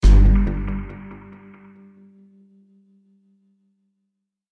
Choose your path - кнопка мгновенного звукового эффекта | Myinstants
choose_your_path_sting.mp3